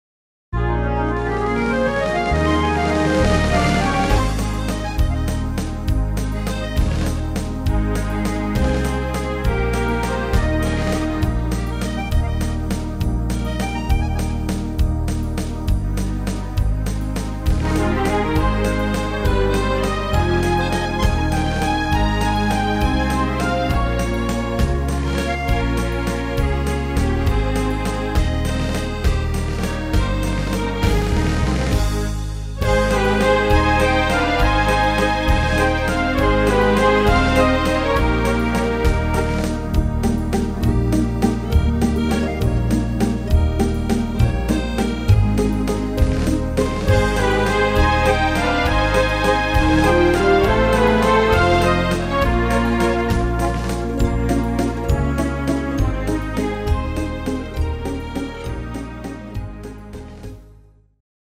Rhythmus  Waltz
Art  Instrumental Orchester, Klassik, Standard